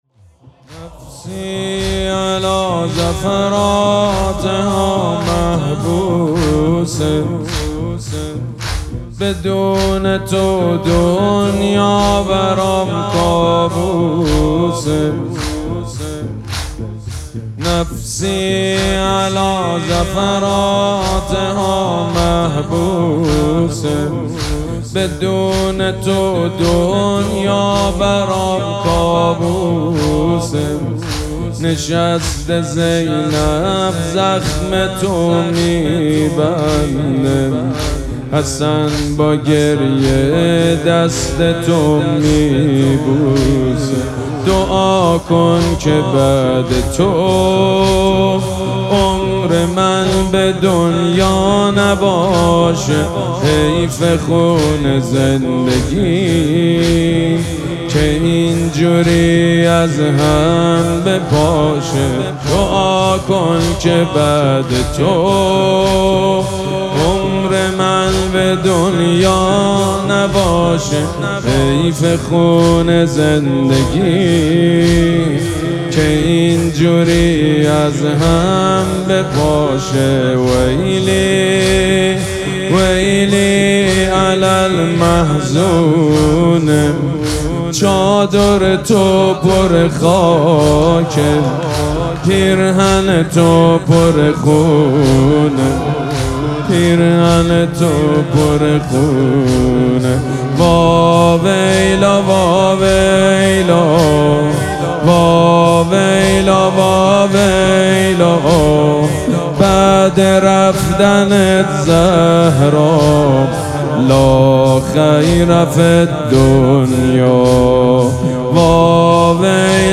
شب چهارم مراسم عزاداری دهه دوم فاطمیه ۱۴۴۶
مداح